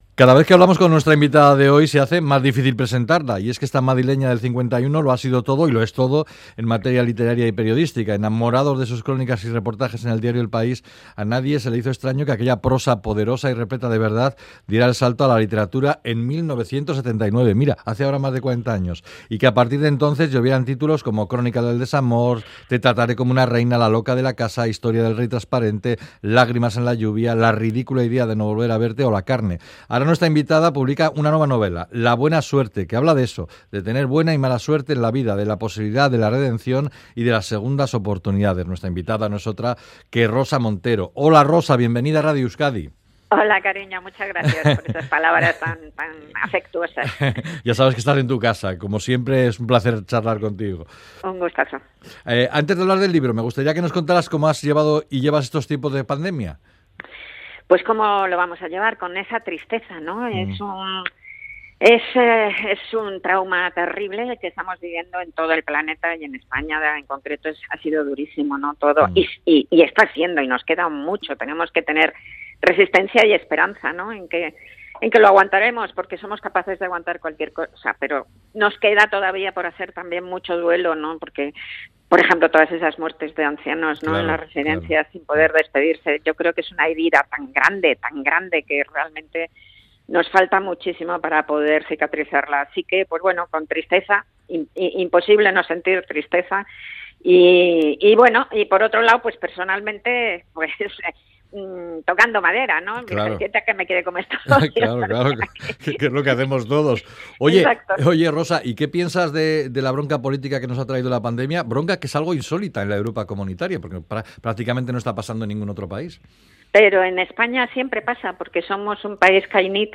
Audio: Charlamos con la periodista y escritora Rosa Montero sobre su nueva novela, "La buena suerte", una historia sobre al búsqueda de la felicidad y la alegría